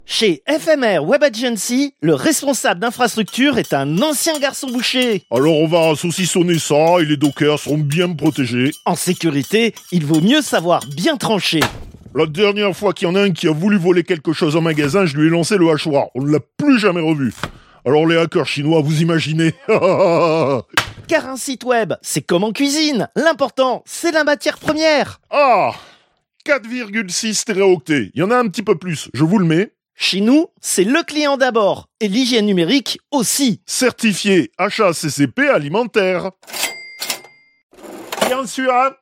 Chroniques › Fausse publicité
Extrait de l'émission CPU release Ex0231 : lost + found (février 2026).